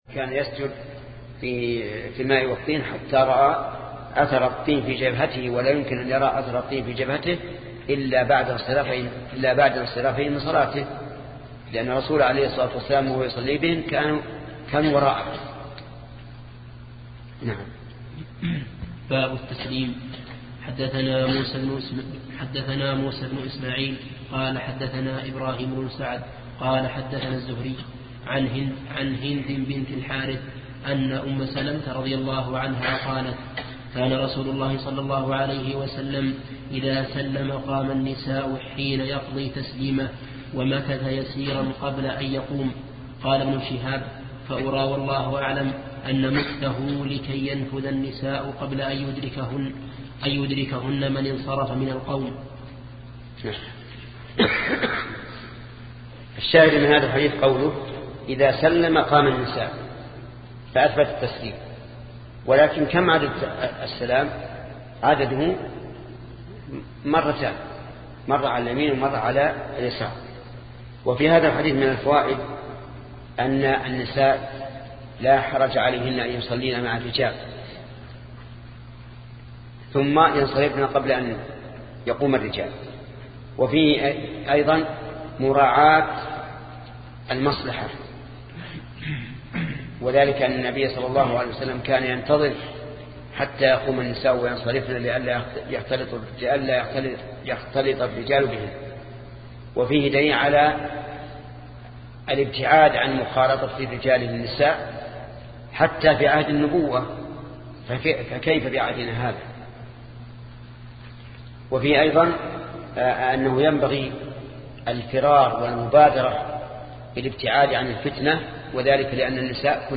شرح صحيح البخاري - الشيخ محمد بن صالح العثيمين